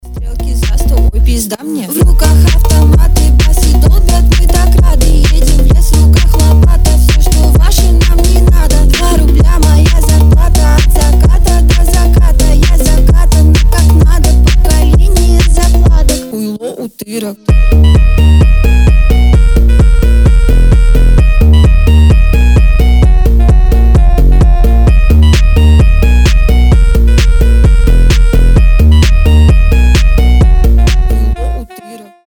• Качество: 320, Stereo
громкие
мощные басы